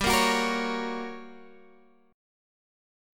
Listen to GmM7b5 strummed